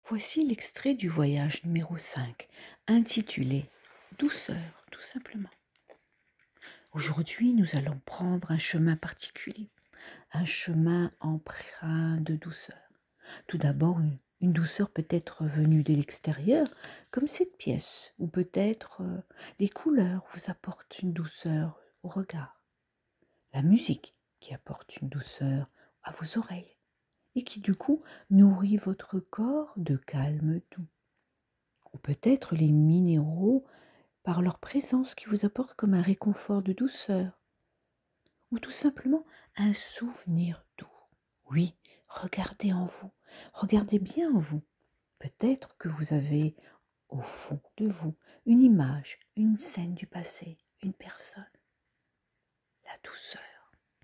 5ème voyage méditatif : Douceur